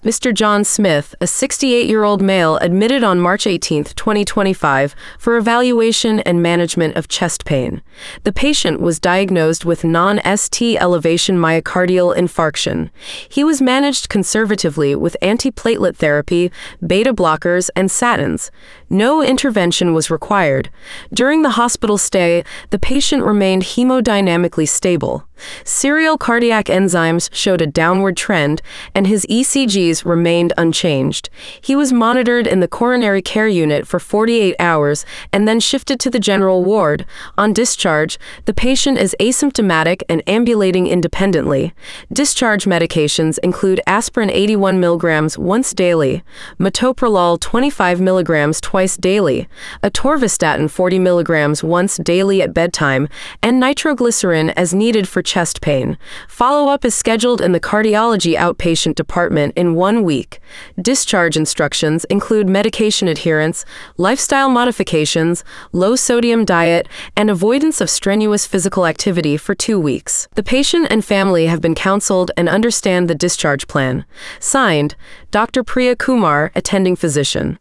This sample includes patient details, diagnosis, treatment, medications, and follow-up instructions—all spoken naturally by the clinician.
dischargesummarysample-1.mp3